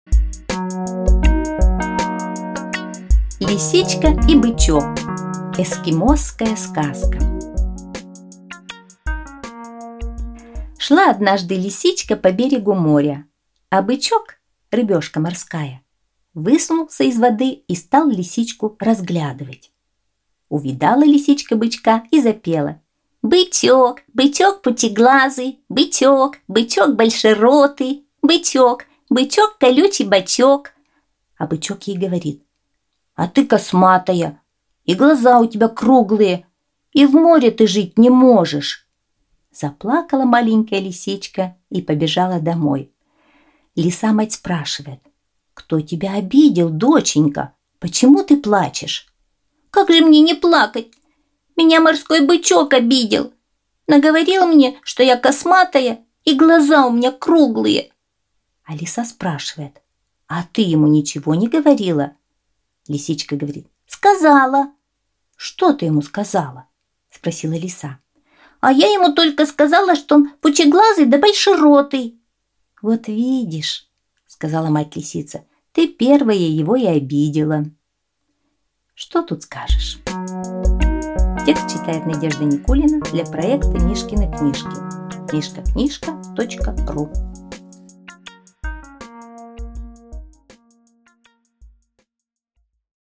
Эскимосская аудиосказка